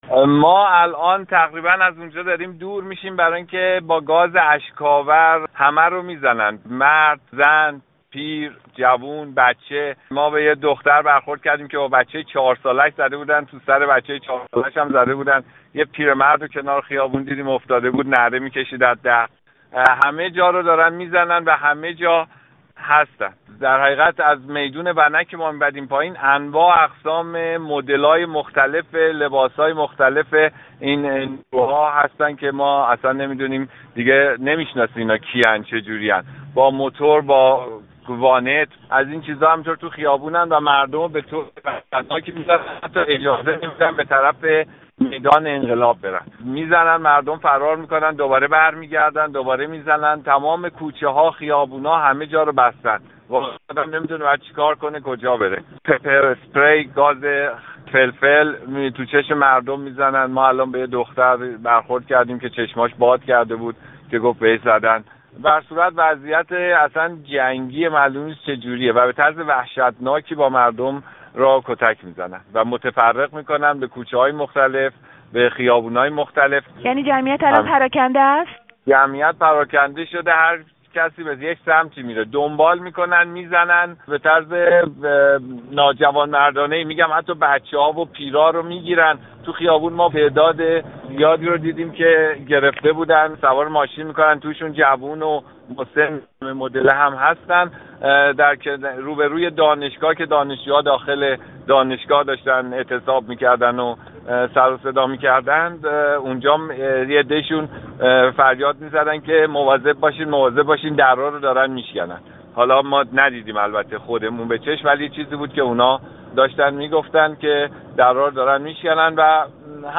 گفت وگوی رادیو فردا با یک شاهد عینی در باره درگیری های امروز تهران